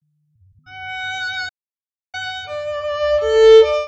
violin (part missing 80ms)